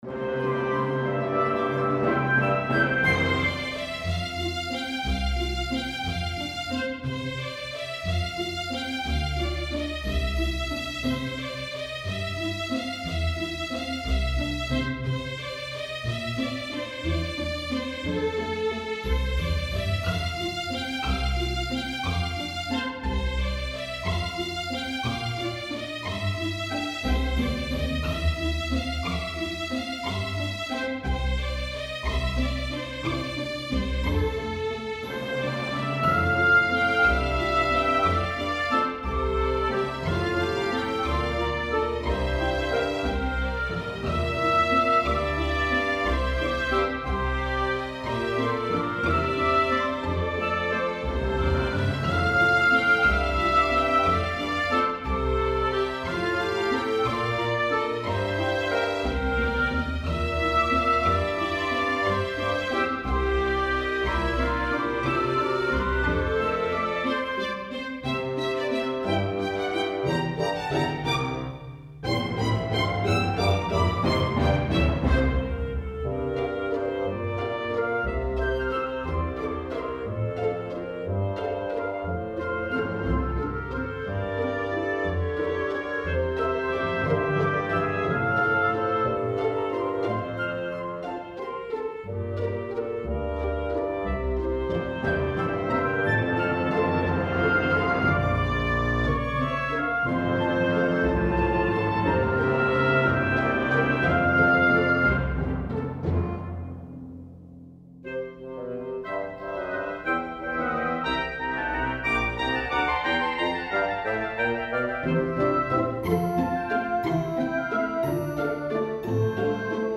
Waltz in F Orchestra, 3:36.
This was my first somewhat reasonable orchestral work (although the strings are a bit dodgy).
default orchestra
Waltz in F.mp3